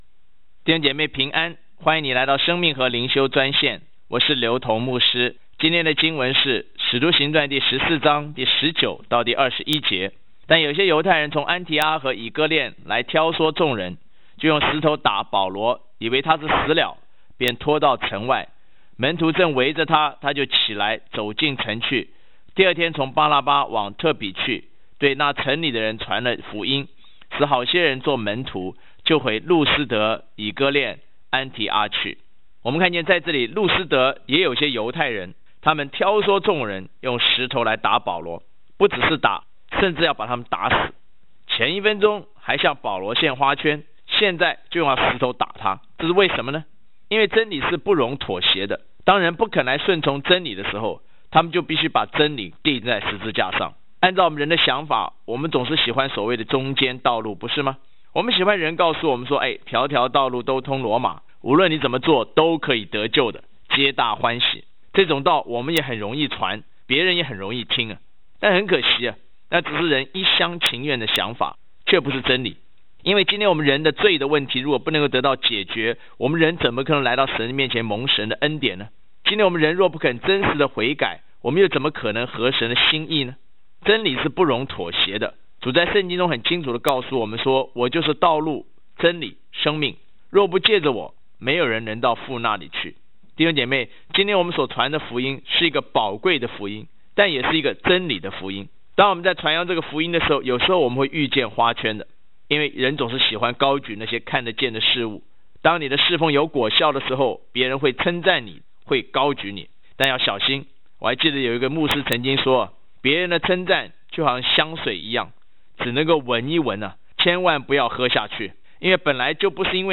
藉着每天五分钟电话分享，以生活化的口吻带领信徒逐章逐节读经